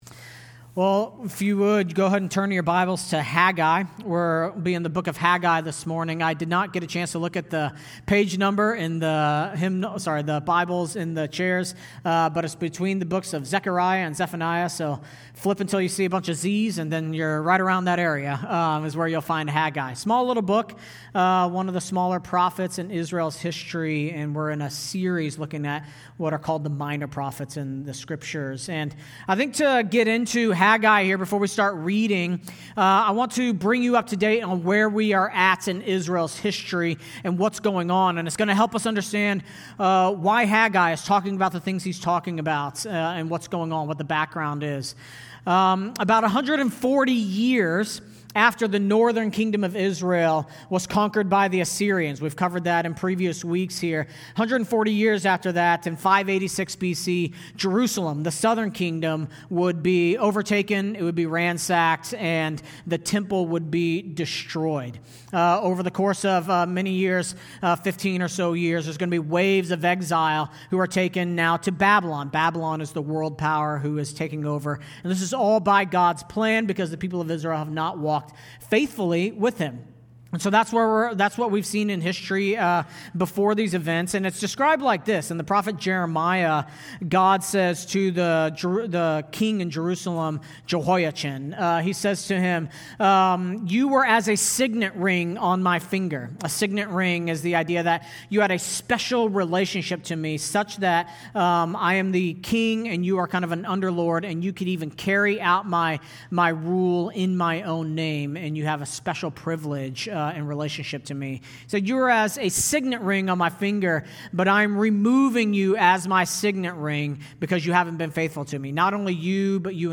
August 17, 2025 (Sunday Morning)